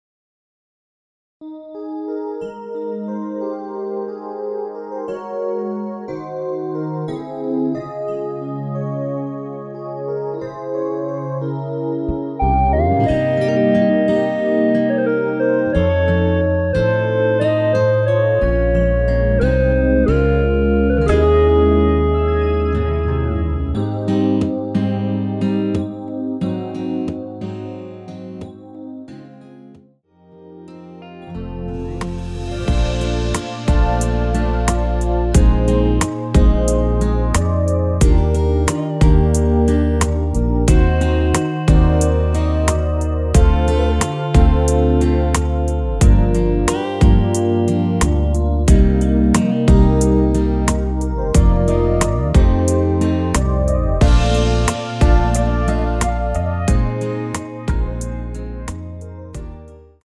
원키에서(-1)내린 MR입니다.
Ab
앞부분30초, 뒷부분30초씩 편집해서 올려 드리고 있습니다.
중간에 음이 끈어지고 다시 나오는 이유는